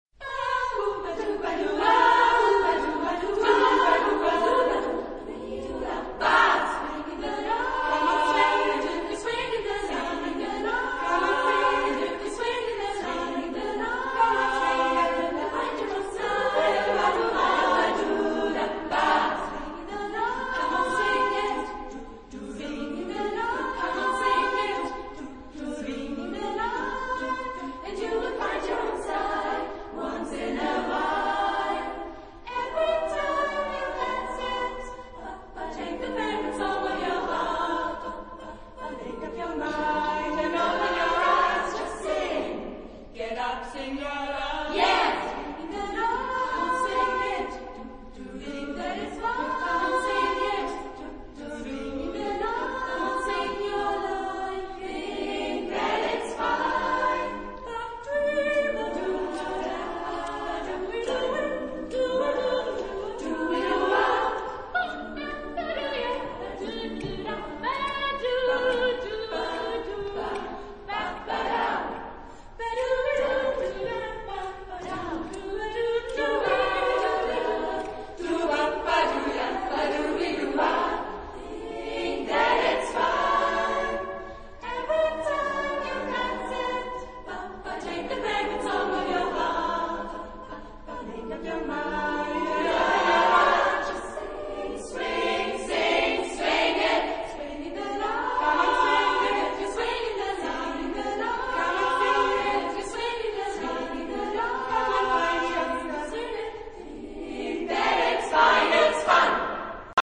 Genre-Style-Forme : Swing ; Variété ; Profane
Type de choeur : SSAA  (4 voix égales de femmes )
Tonalité : sol majeur